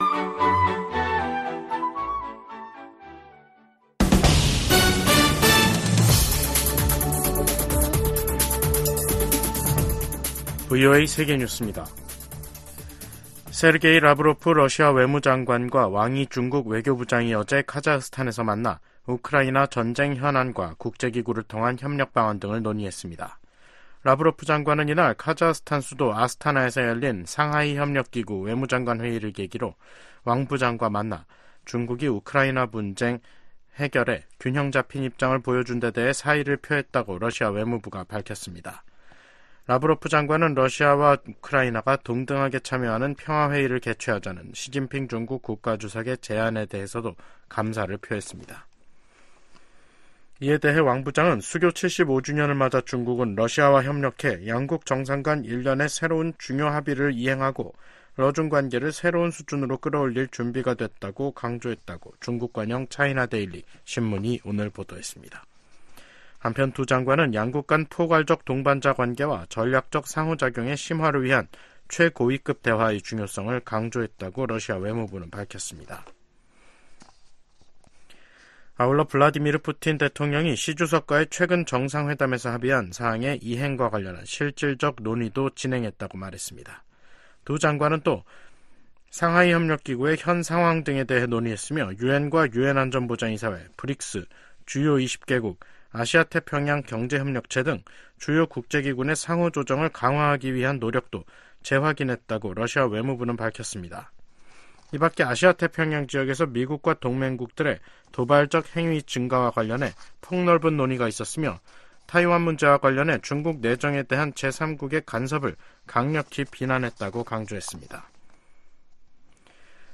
VOA 한국어 간판 뉴스 프로그램 '뉴스 투데이', 2024년 5월 21일 2부 방송입니다. 최근 북한과 러시아의 협력 강화는 중국도 우려해야 할 사안이라고 미 국무부가 지적했습니다. 미국이 유엔 무대에서 북한과 러시아 간 불법 무기 이전을 비판하면서, 서방의 우크라이나 지원을 겨냥한 러시아의 반발을 일축했습니다. 유럽연합 EU는 러시아가 중국과의 정상회담 후 북한 옹호성명을 발표한 데 대해 기회주의적인 선택이라고 비판했습니다.